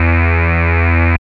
74.09 BASS.wav